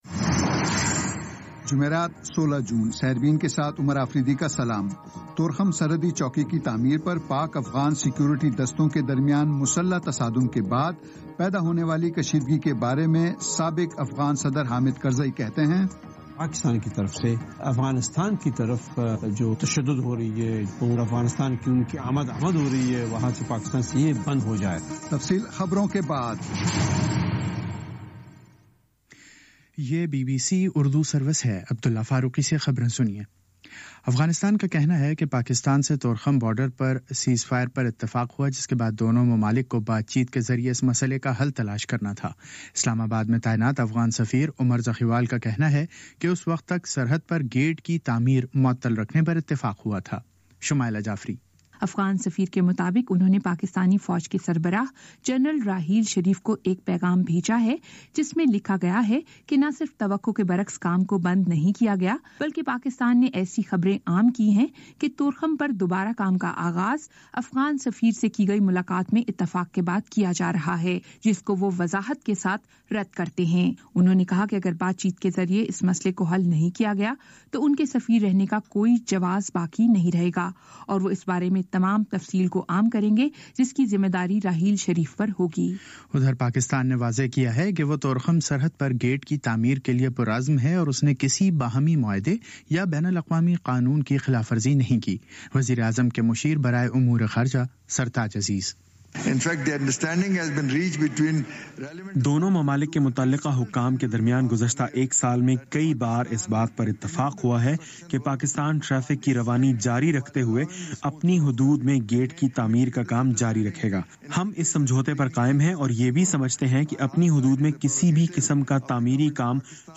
جمعرات 16 جون کا سیربین ریڈیو پروگرام